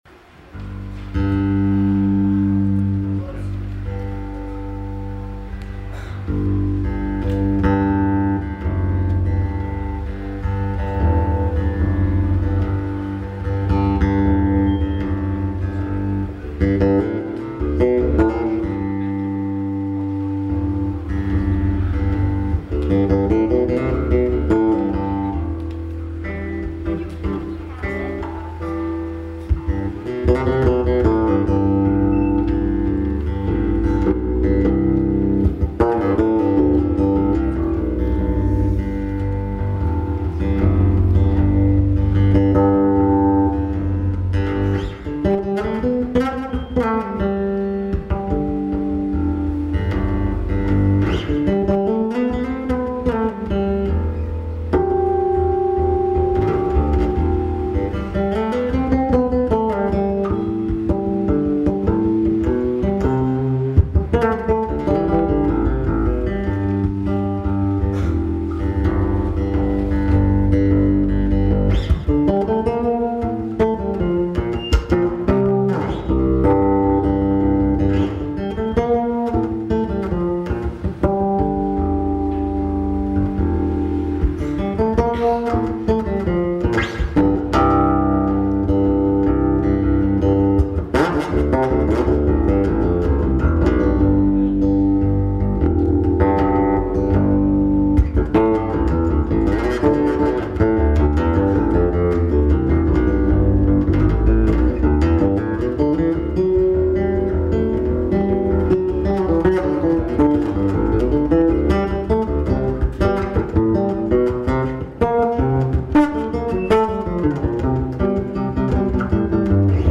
Recorded on 3/27/02 at Mars Music, Omaha, NE